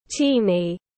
Nhỏ xíu tiếng anh gọi là teeny, phiên âm tiếng anh đọc là /ˈtiːni/ .
Teeny /ˈtiːni/